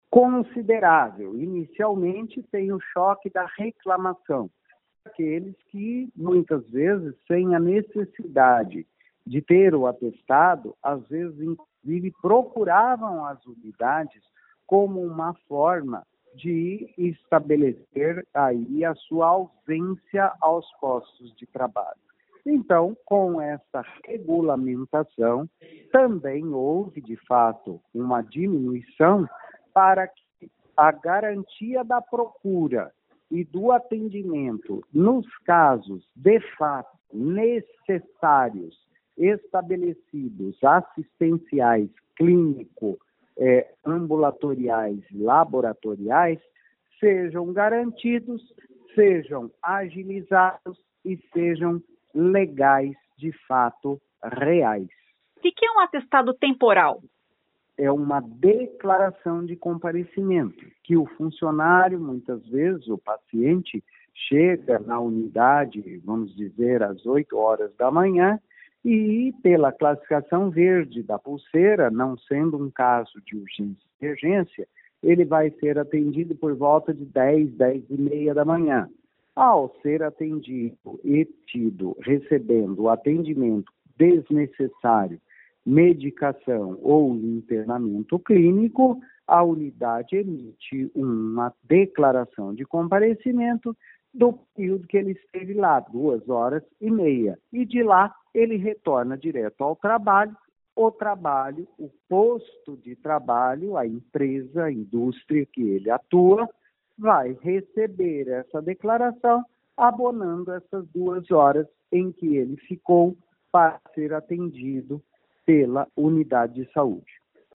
O secretário de Saúde Antônio Carlos Nardi diz que já houve uma redução considerável no número de atestados médicos emitidos na saúde pública.